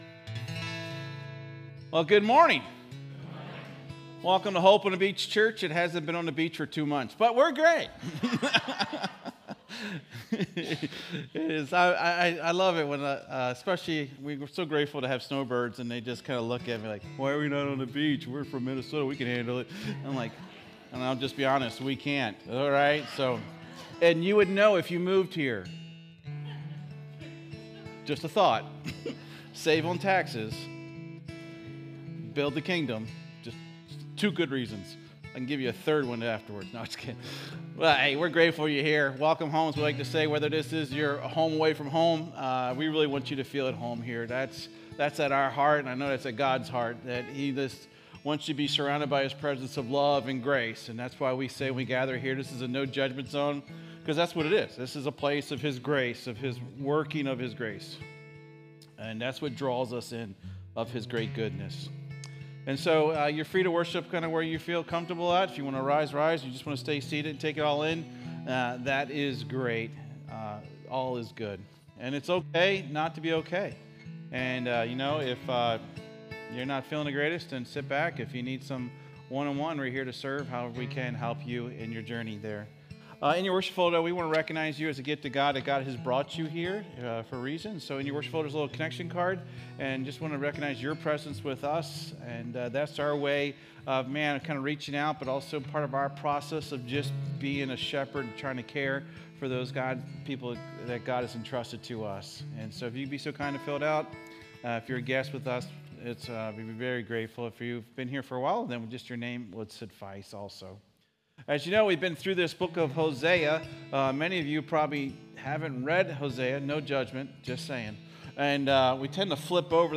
SERMON DESCRIPTION In Hosea, God reveals Himself as both Judge and Healer.